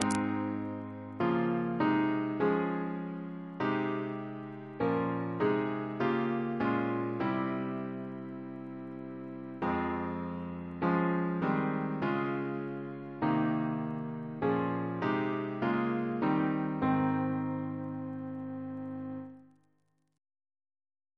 Double chant in A♭ Composer: Alec Wyton (1921-2007), Organist of St. John the Divine Reference psalters: ACP: 239